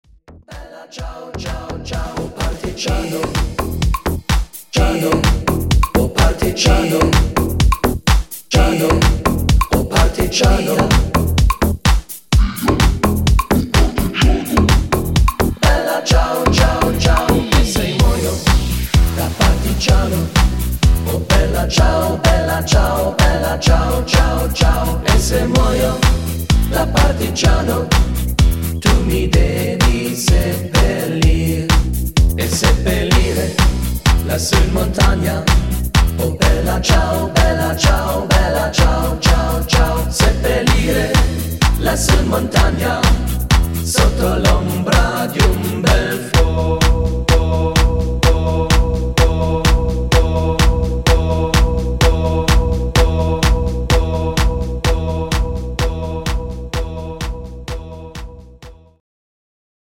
Rhythmus  Disco
Art  Internationale Popmusik, Italienisch, Pop